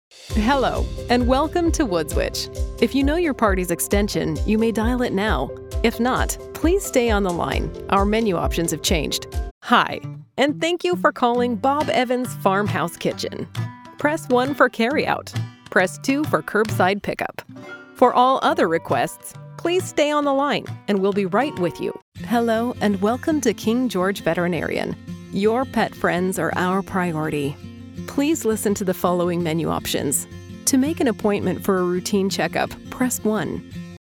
English (Canadian)
IVR
TLM 103 & Audiotechnica 3035
Pro Sound Studio - custom built